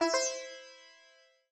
sitar_ec1.ogg